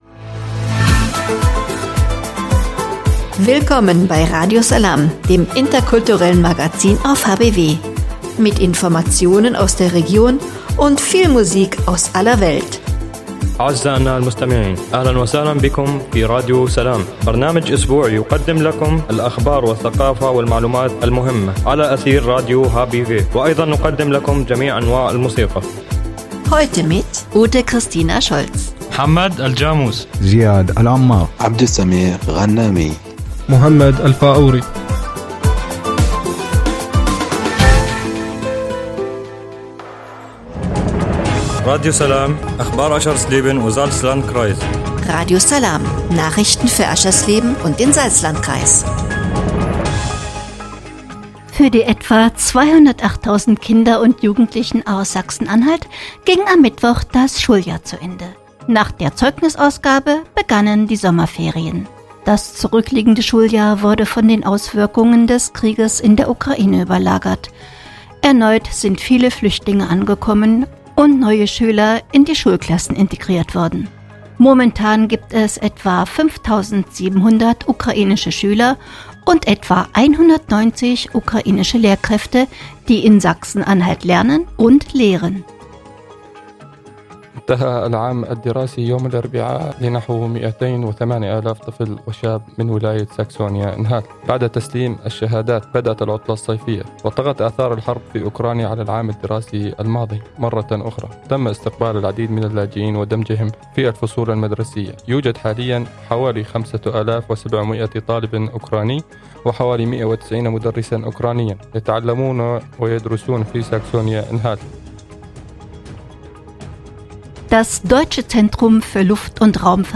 „Radio Salām“ heißt das interkulturelle Magazin auf radio hbw.
(Hinweis: Die in der Sendung enthaltene Musik wird hier in der Mediathek aus urheberrechtlichen Gründen weggelassen.)